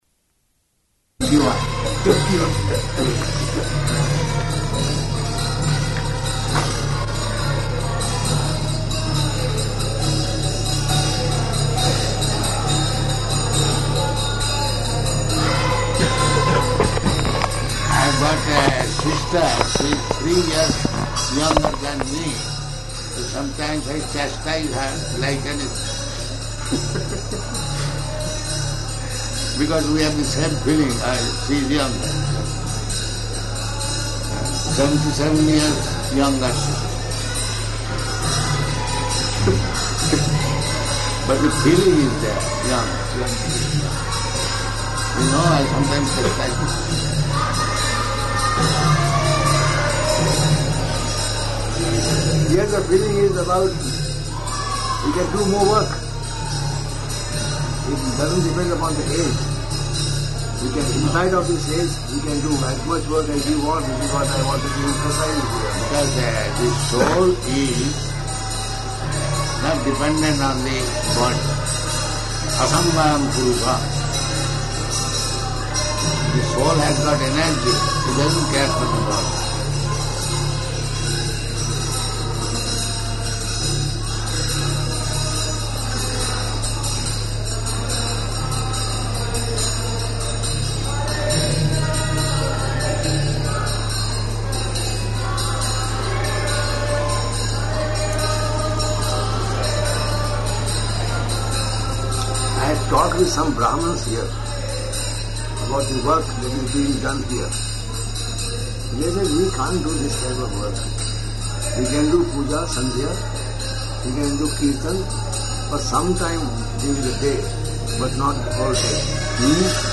Room Conversation
Room Conversation --:-- --:-- Type: Conversation Dated: August 24th 1976 Location: Hyderabad Audio file: 760824R2.HYD.mp3 Indian man: [indistinct] Prabhupāda: I have got a sister.